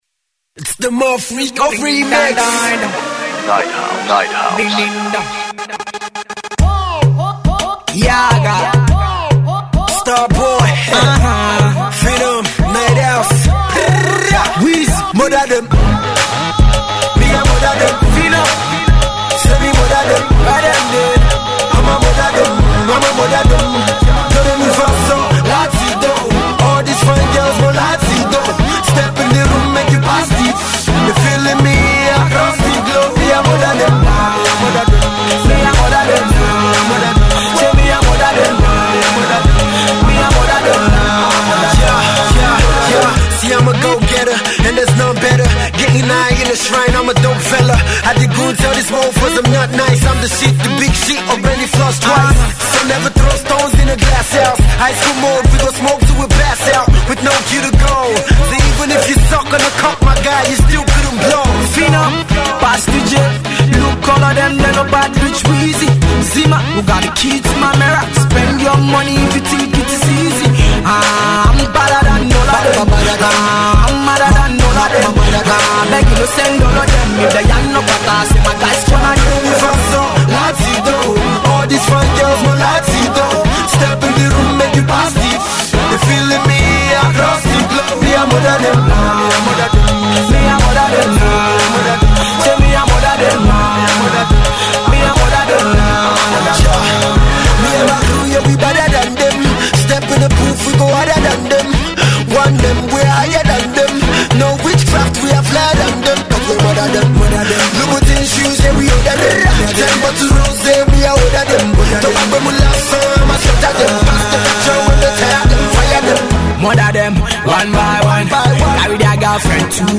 Hip-Hop cut